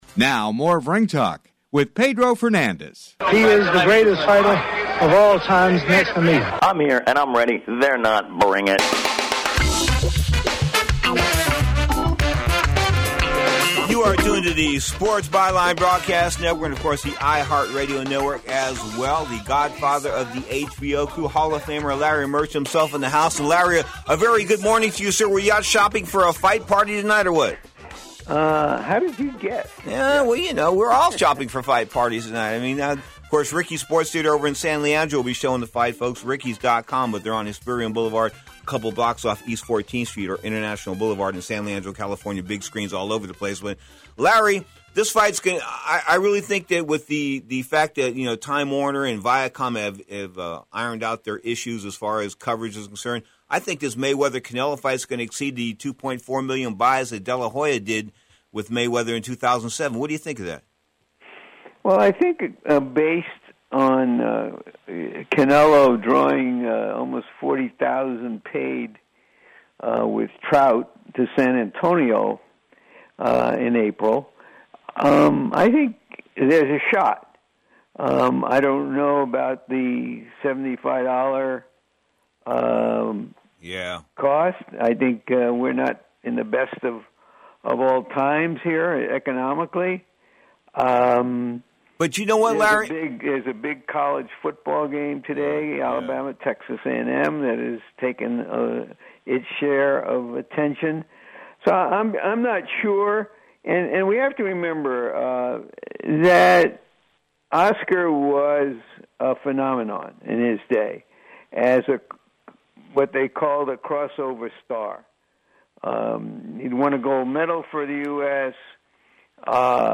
Podcast: Play in new window | Download Floyd “The Mexecutioner” Mayweather GODFATHER OF TELEVISED BOXING ON MAYWEATHER-CANELO CARD San Francisco, CA- This interview with Larry Merchant is taken from the Saturday (9/14/13) edition of “ Ring Talk Worldwide .” Larry, who has had beefs with Floyd Mayweather , discusses the three top fights tonight, Mayweather- Canelo Alvarez, Danny Garcia-Lucas Matthysse & Ishe Smith-Carlos Molina .